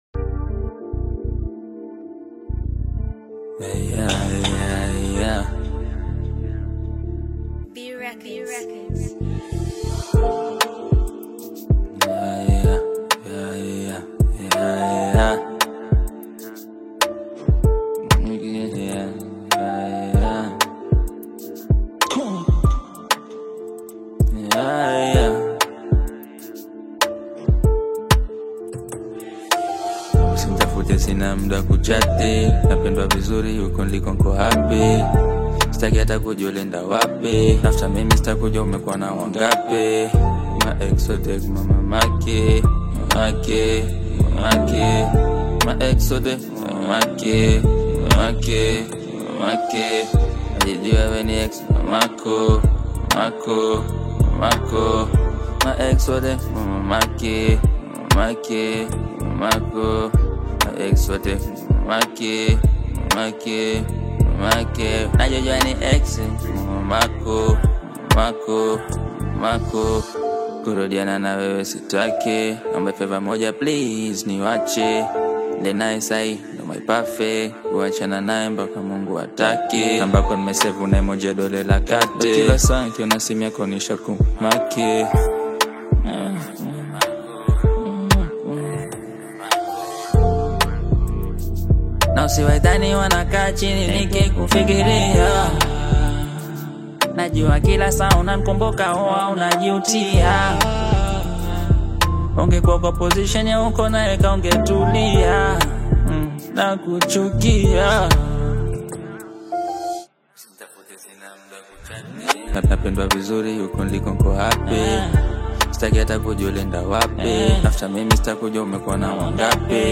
AfrobeatAudio
is an emotional Afro-Pop/hip-hop single